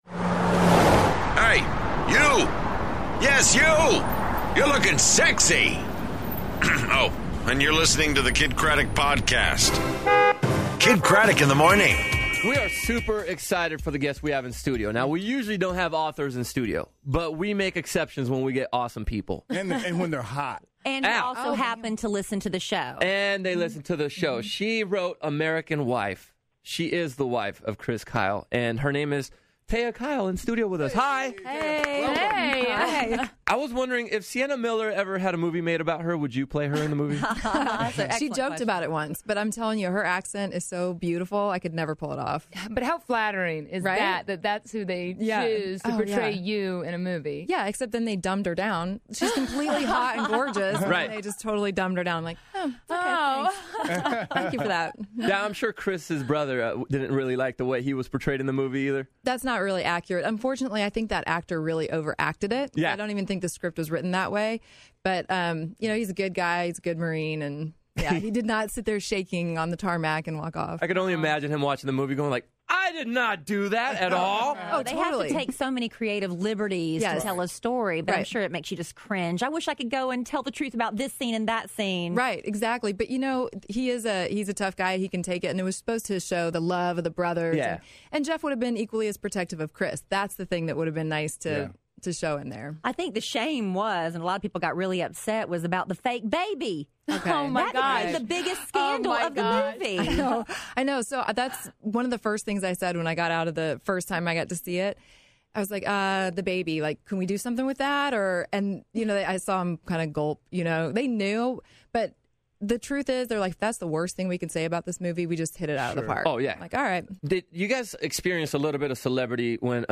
American Sniper's Wife, Taya In Studio, Beat The Bank, And Tinder Tuesday